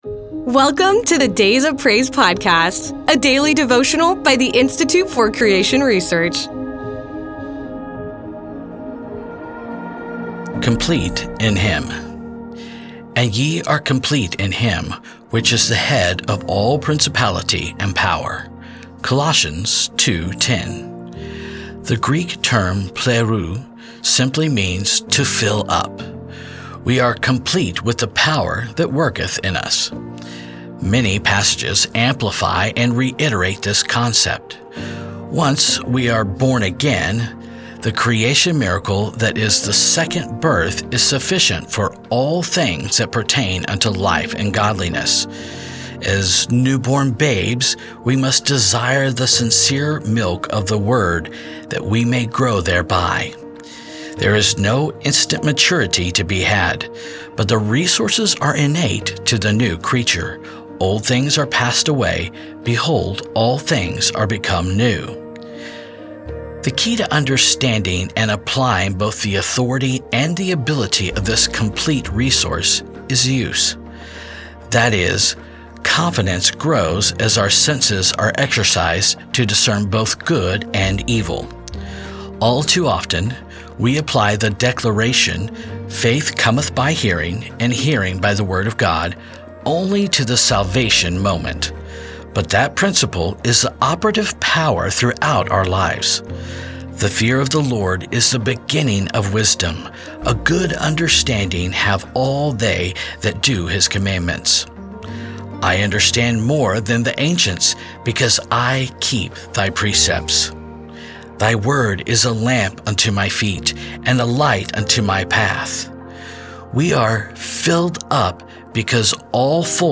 Creation Devotional Biblical Truth Faith Salvation Spirituality